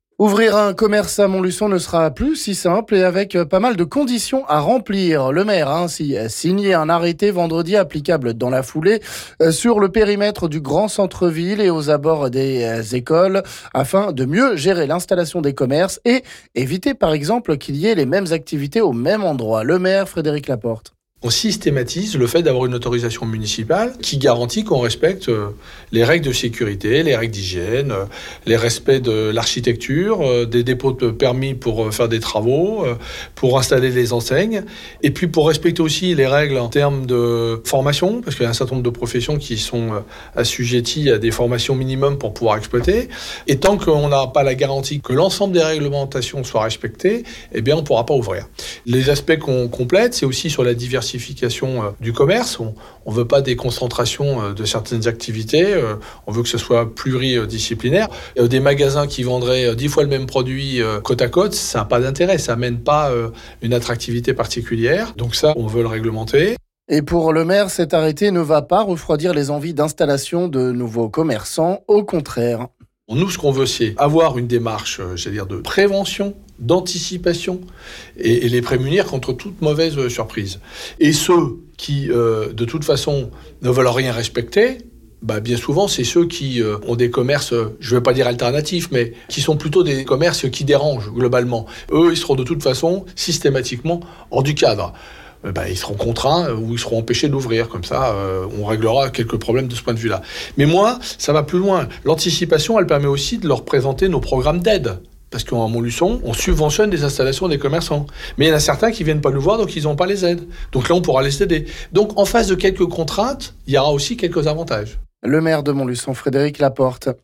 Le maire de Montluçon Frédéric Laporte s'explique ici...